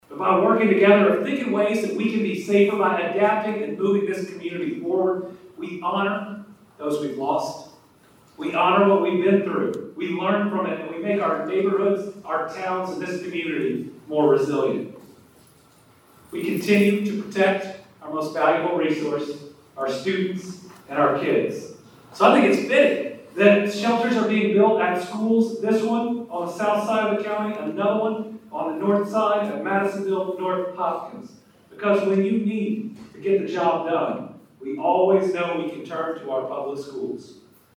A moment of pride and resilience was on full display in Hopkins County as Governor Andy Beshear, school officials, and community members gathered to celebrate the opening of new auxiliary gyms and storm shelters at both local high schools Tuesday afternoon.